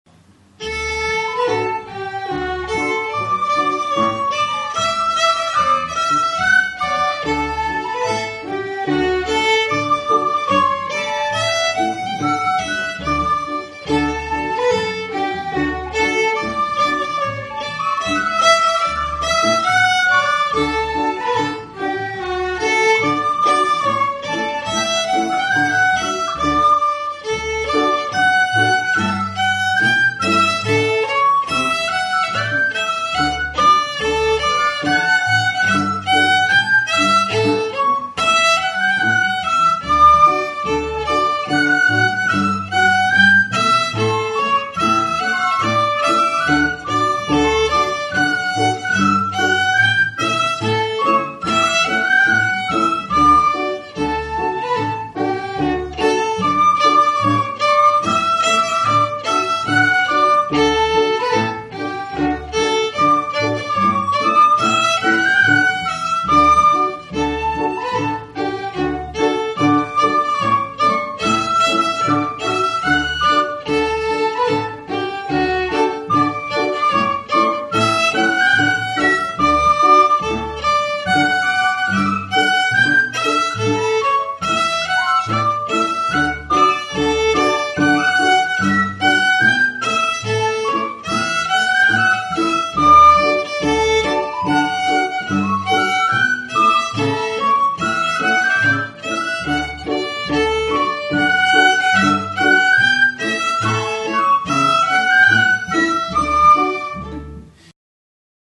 Reel - D Major